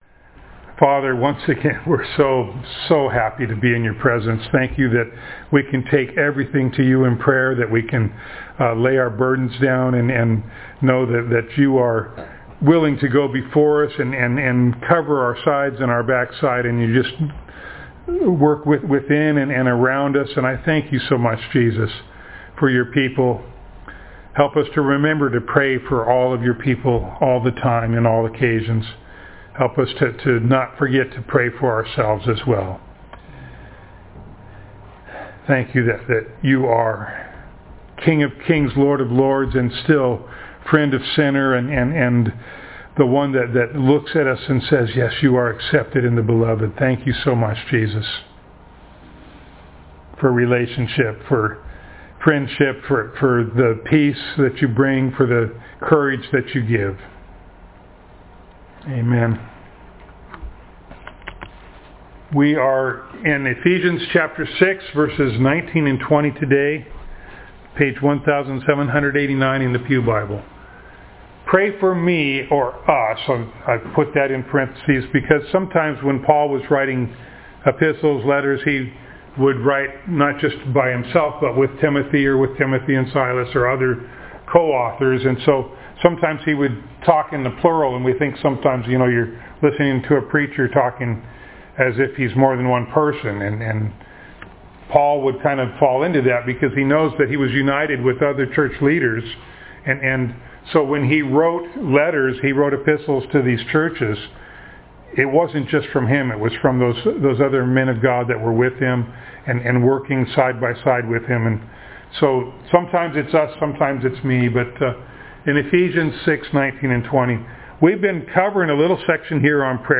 Ephesians Passage: Ephesians 6:19-20 Service Type: Sunday Morning Download Files Notes « Easter 2021 from Leviticus Tychicus